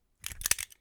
dungeons/Assets/HurricaneVR/Framework/SFX/Magazine.wav at 1a9e2a3ee8e09ab49c49a97254ede16ed8d2aed2
Magazine.wav